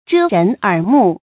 遮人耳目 注音： ㄓㄜ ㄖㄣˊ ㄦˇ ㄇㄨˋ 讀音讀法： 意思解釋： 玩弄手法，掩蓋真相。